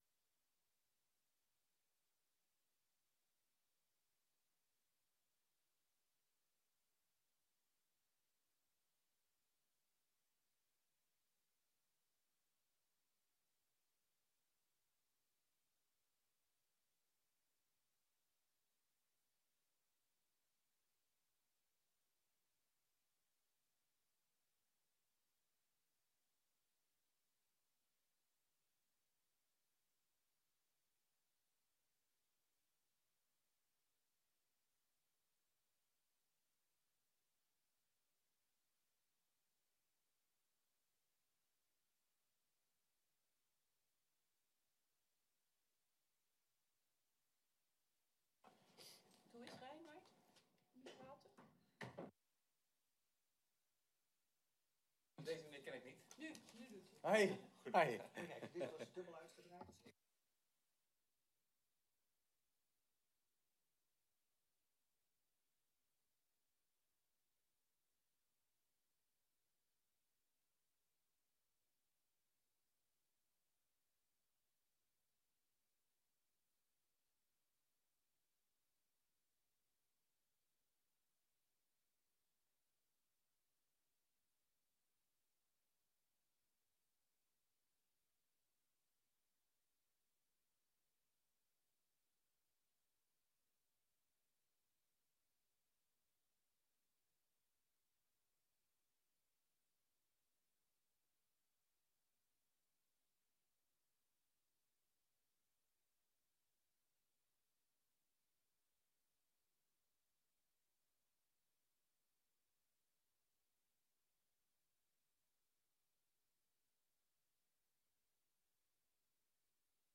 Download de volledige audio van deze vergadering
Locatie: Voorrondezaal Lingewaal